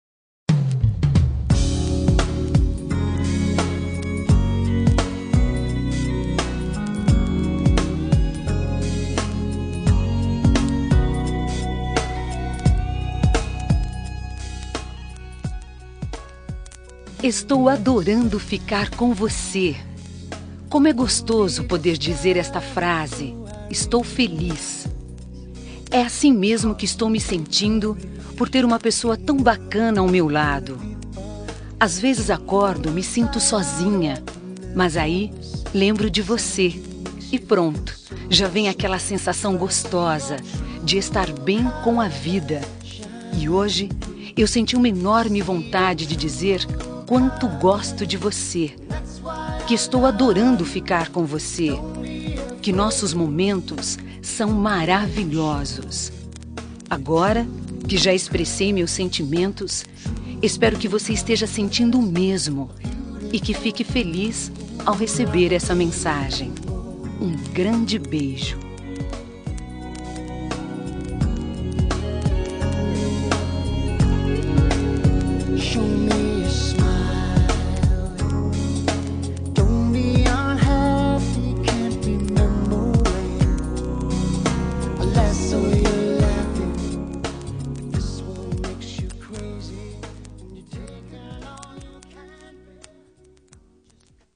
Telemensagem Estou Adorando Ficar com você – Voz Feminina – Cód: 8085 – Linda
8085-adorando-ficar-com-voce-fem.m4a